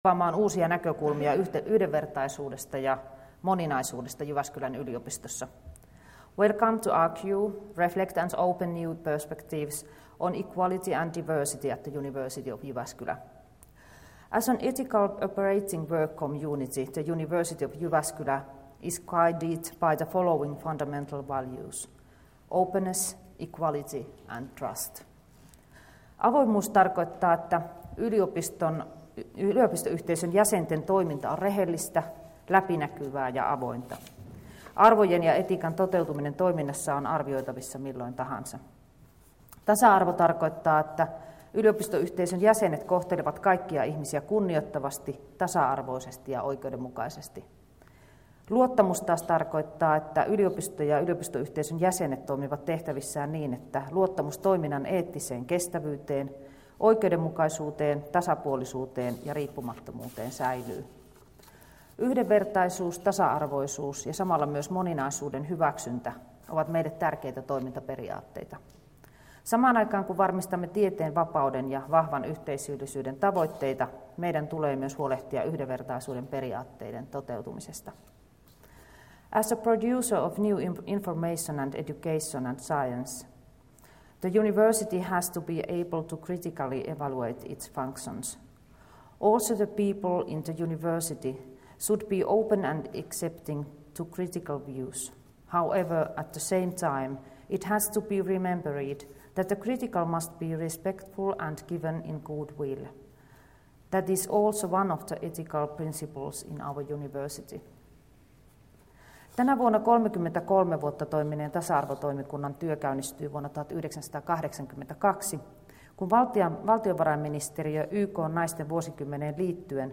Tilaisuuden avaus — Moniviestin